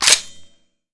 svd_boltforward.wav